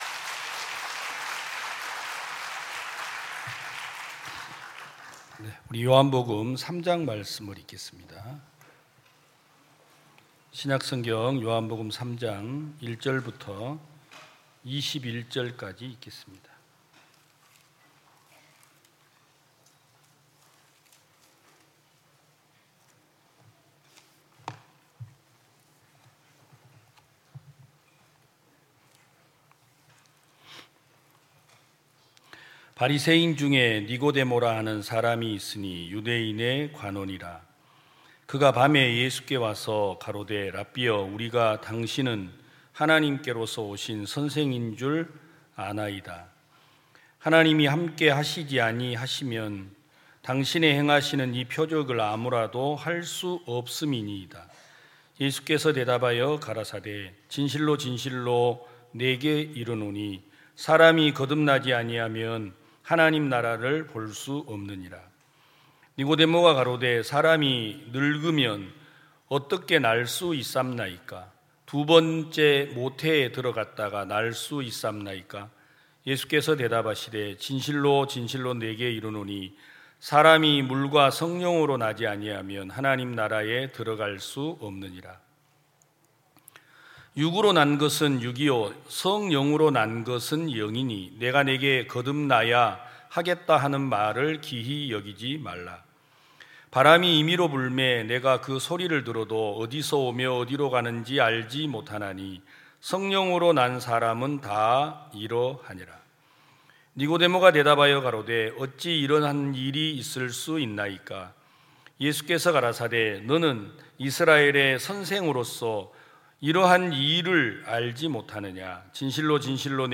2022 여름 캠프(강릉) 3차 #5 하나님께서 원하시는 것과 사람이 원하는 것 좋아요 즐겨찾기 프로그램 소개 프로그램 응원 공유 다운로드 태그 이 콘텐츠를 보시고 떠오르는 단어는 무엇입니까?